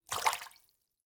splash-v1.ogg